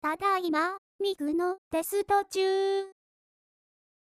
（↑唄うソフトだけに喋らせる方が難しいのよね……ってマテ ｗ）